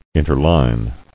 [in·ter·line]